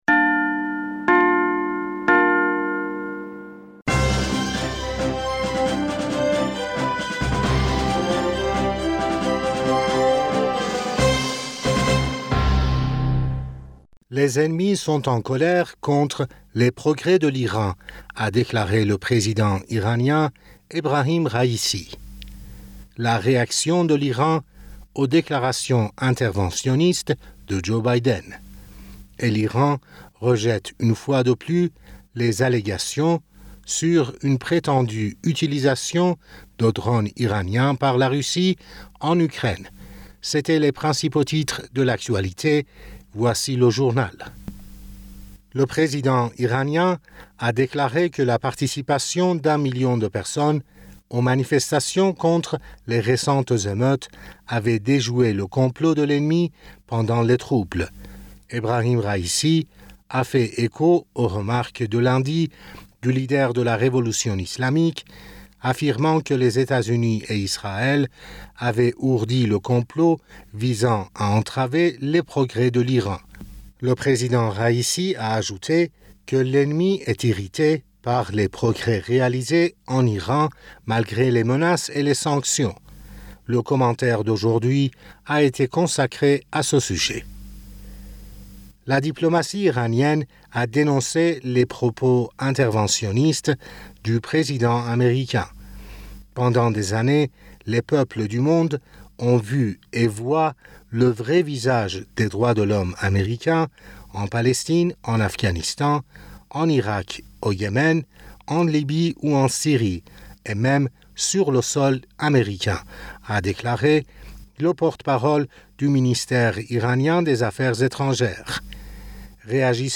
Bulletin d'information Du 04 Octobre